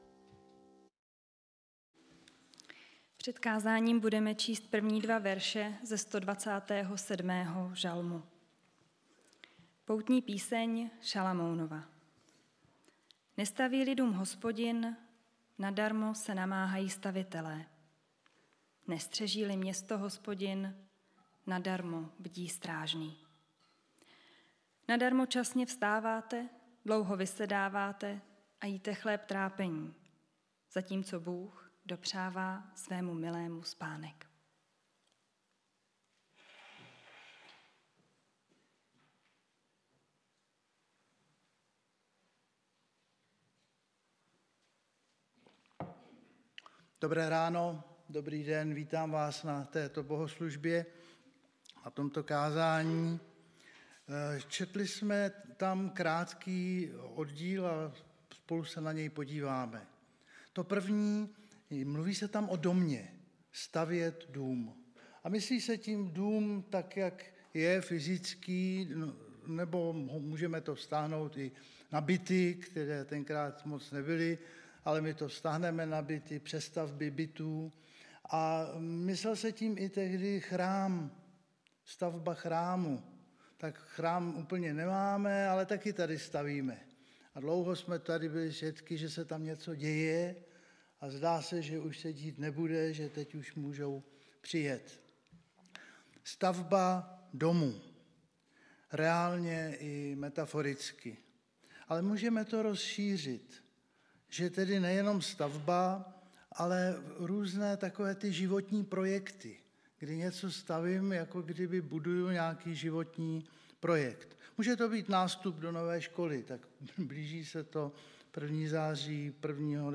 Nedělní bohoslužby přehrát